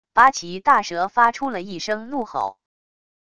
八岐大蛇发出了一声怒吼wav音频